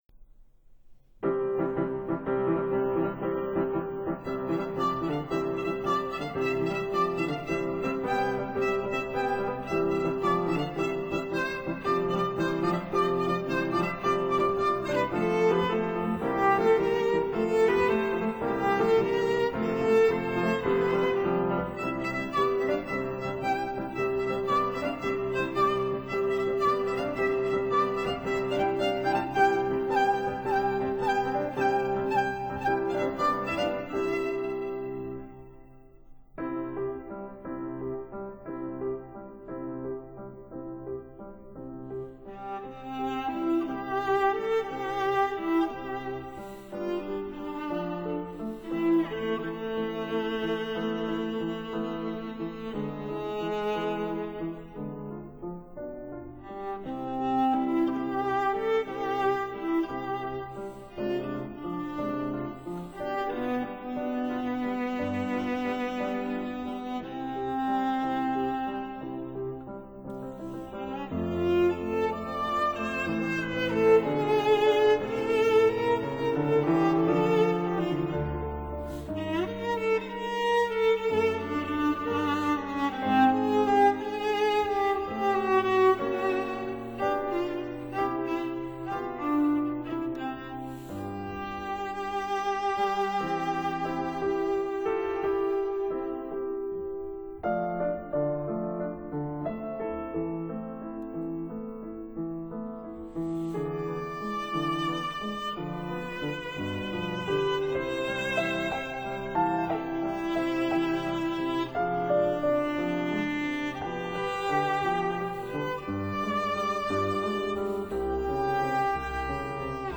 五一節安好 中提琴樂曲輯 Various Composers
viola
piano